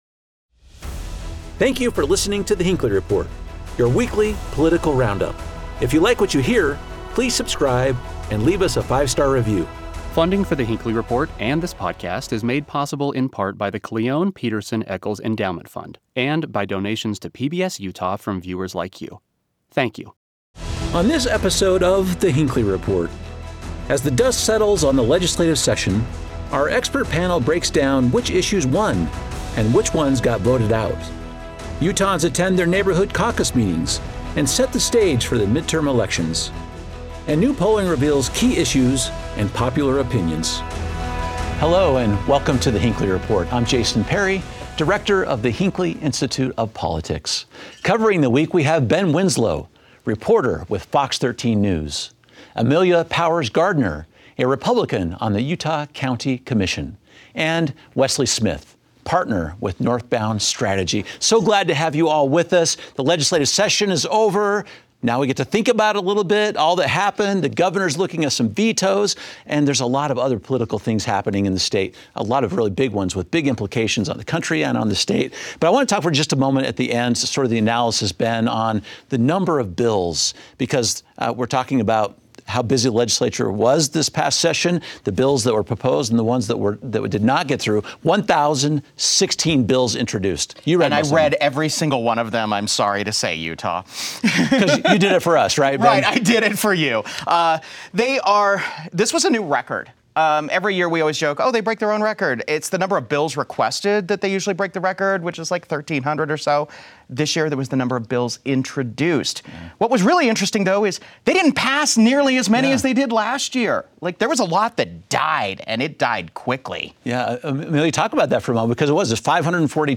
Our expert panel discusses how the executive branch engages with the lawmaking process.